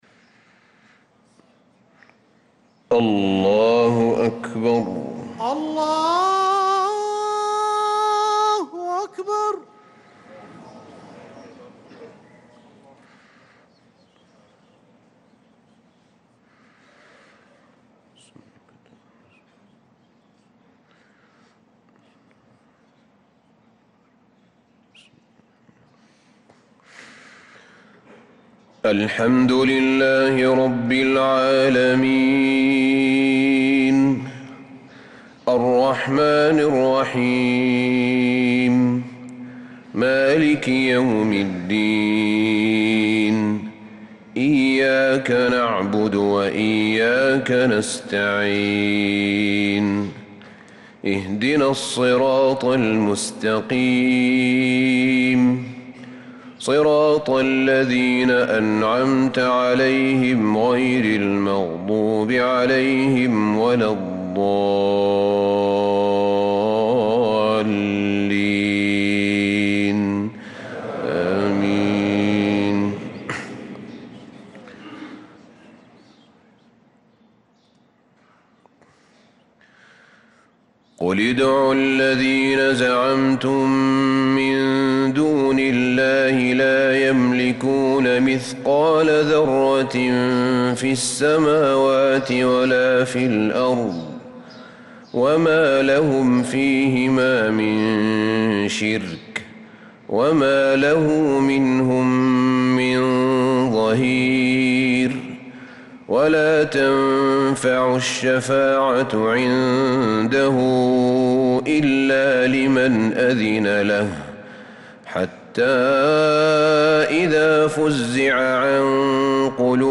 صلاة الفجر للقارئ أحمد بن طالب حميد 18 رجب 1446 هـ
تِلَاوَات الْحَرَمَيْن .